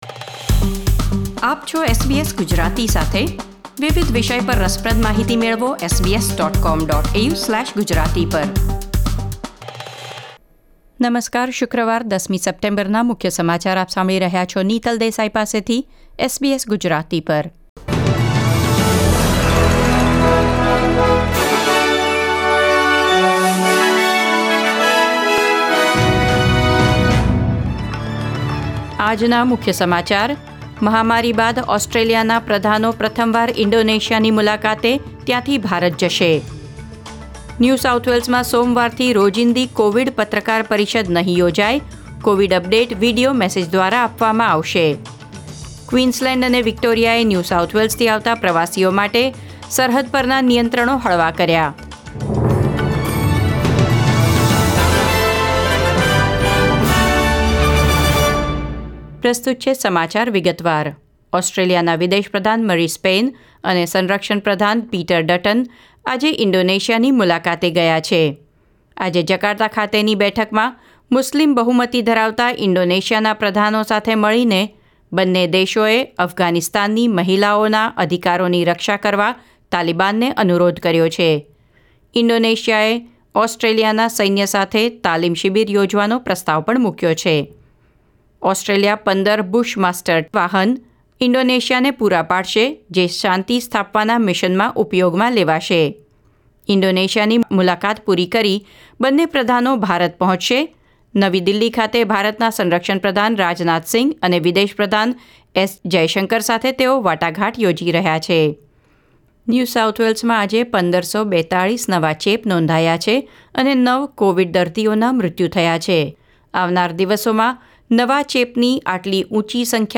SBS Gujarati News Bulletin 10 September 2021